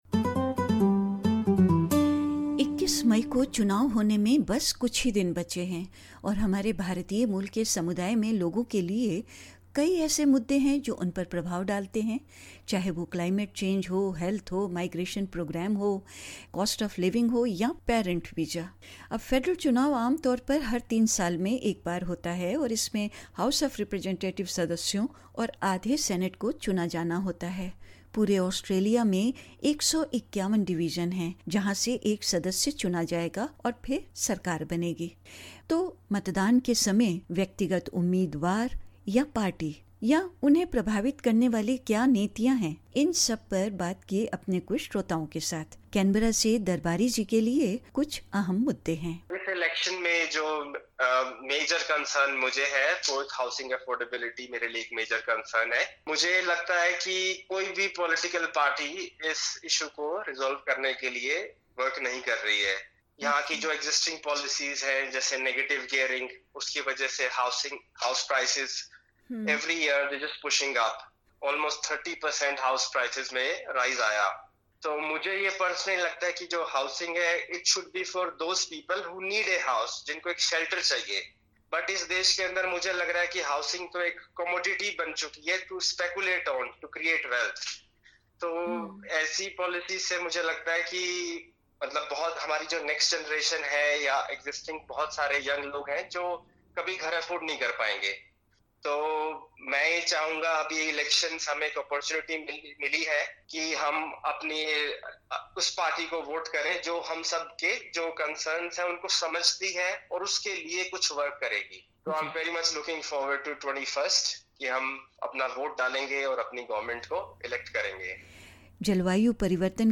With only two days left for the federal election, both major parties have outlined their key policies and ramped up their campaigns. SBS Hindi spoke to some Indian Australians about their parameters for voting as well as issues while they are carefully evaluating major parties’ key policies.